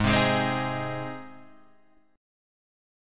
G# Major
G# chord {4 3 6 5 4 4} chord